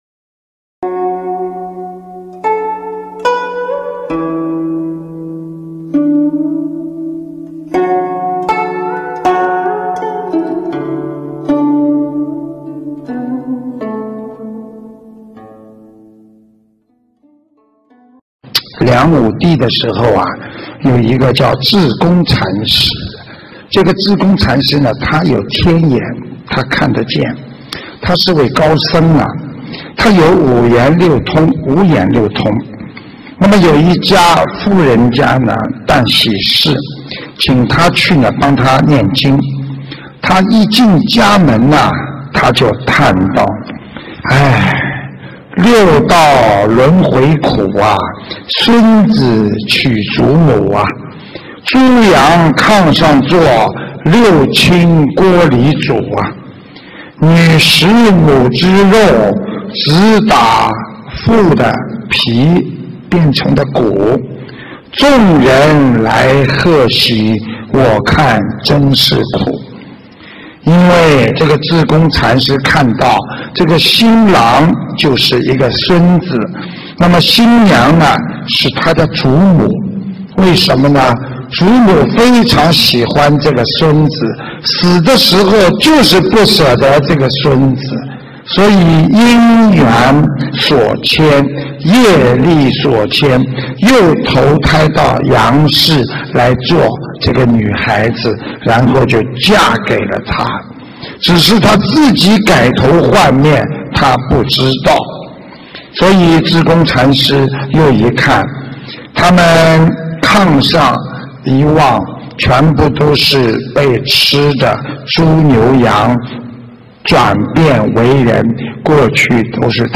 音频：《志公禅师观因果》新加坡观音堂开光开示！